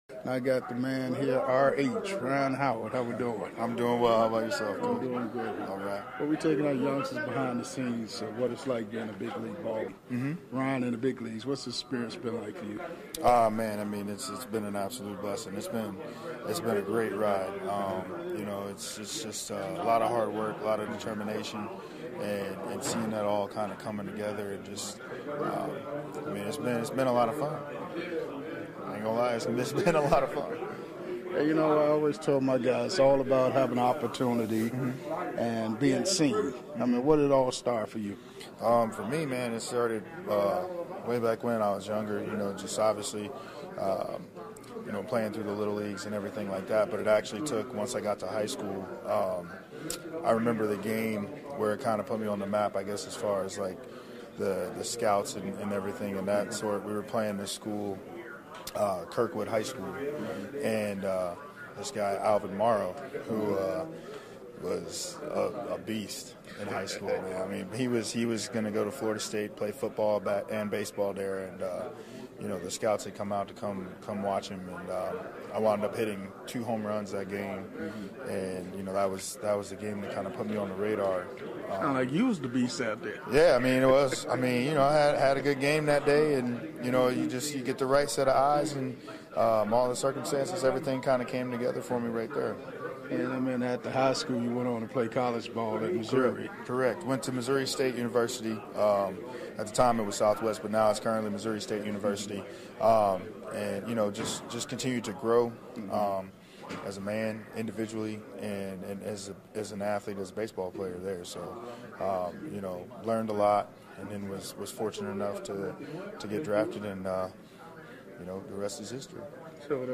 Interviews
full uncut and unedited interview